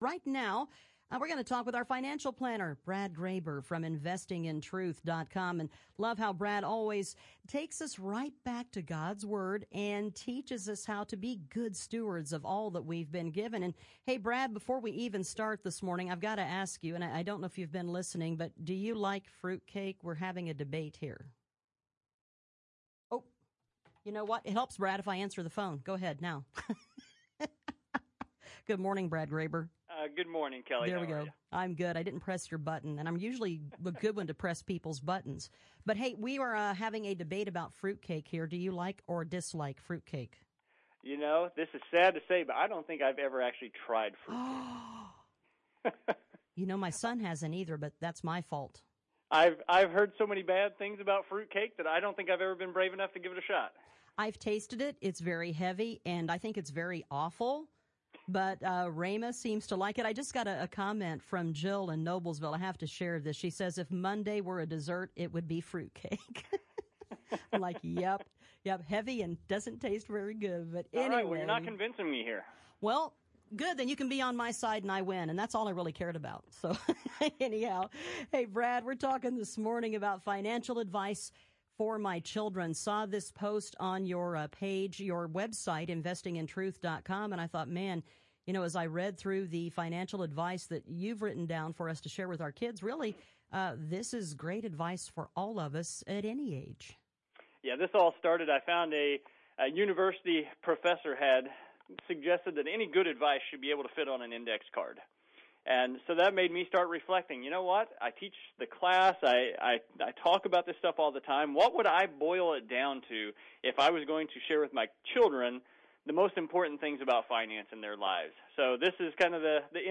In the following interview, we go through my short list of financial advice.